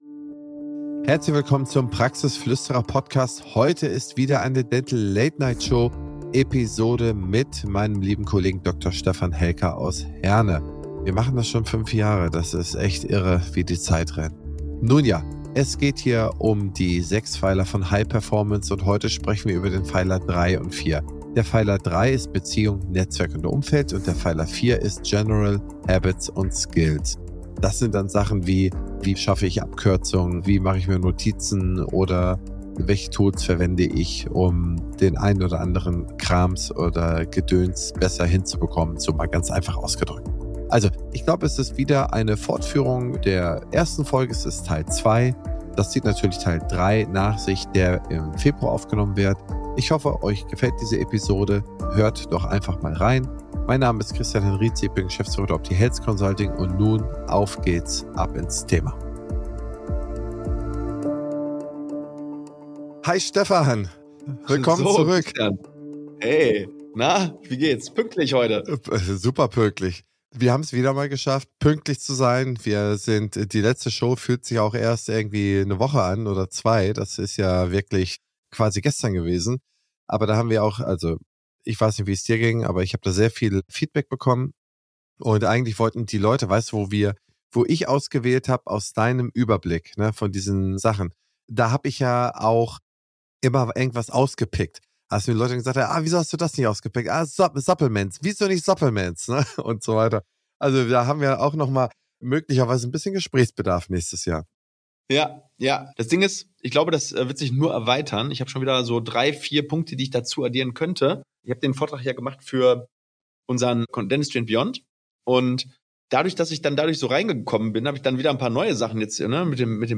In dieser Episode, einer Aufzeichnung der Dental Late Night Show